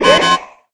enemy detected.ogg